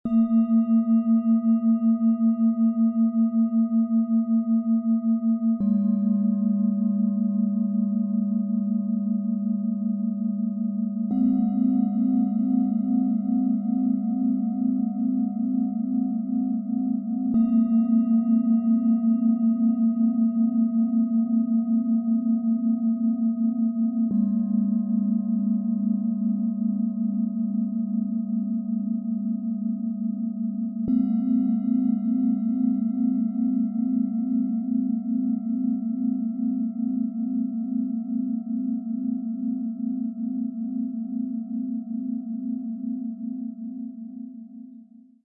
OM Klang - Innerer Frieden & Weite - tief, verbindend, spirituell - Set aus 3 Klangschalen, Ø 15,6 - 18,5 cm, 2,44 kg
Tiefster Ton – tragend, verbindend, frei
Ihr Klang wirkt erdend, befreiend und tief öffnend.
Mittlerer Ton – ankommend, zentrierend, warm
Höchster Ton – lichtvoll, erweiternd, inspirierend
Im Sound-Player - Jetzt reinhören können Sie den Original-Ton genau dieser Schalen, des Sets anhören.
Bengalen Schale, Om Mani Padme Hum in Schwarz-Gold, 18,5 cm Durchmesser, 9,6 cm Höhe
MaterialBronze